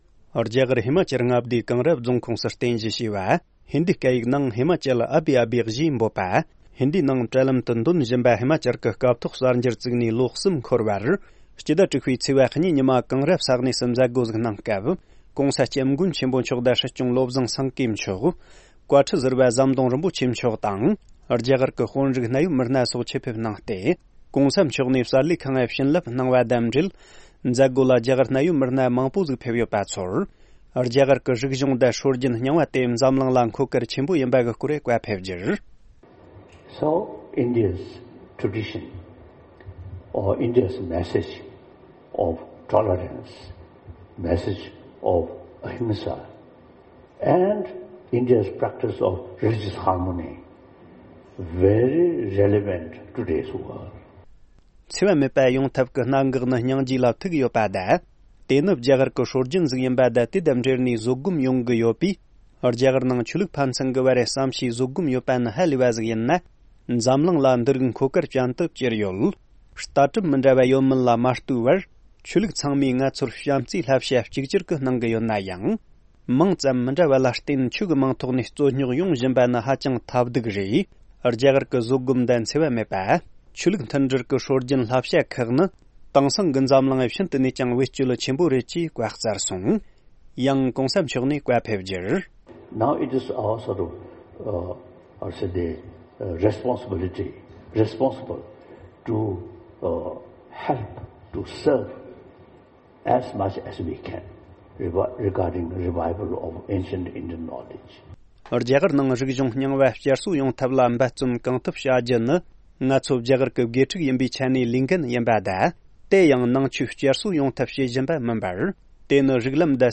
ཧི་མ་ཅལ་ཨ་བྷི་ཨ་བྷི་གསར་ཁང་བཙུགས་ཏེ་ལོ་གསུམ་འཁོར་བའི་མཛད་སྒོ་ཐོག་༧གོང་ས་མཆོག་ནས་བཀའ་སློབ།
སྒྲ་ལྡན་གསར་འགྱུར། སྒྲ་ཕབ་ལེན།